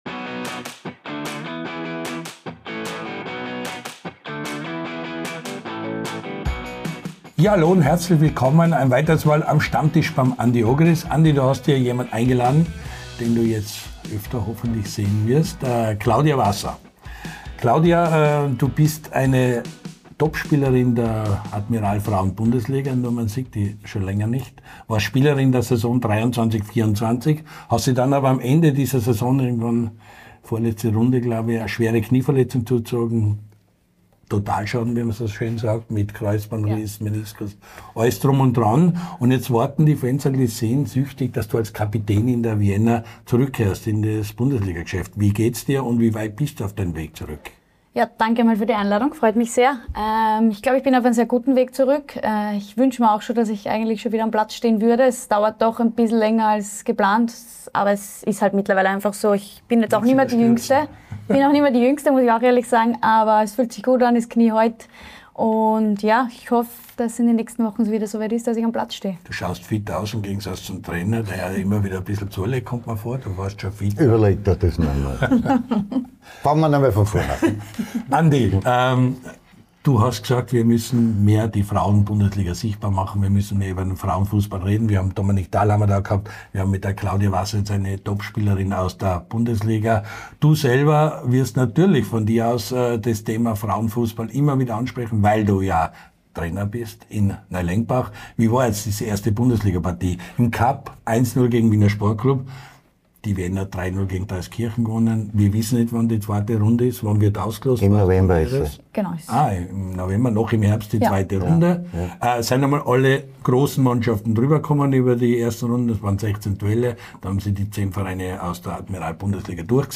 Der LAOLA1-Kult-Talk von und mit Andy Ogris!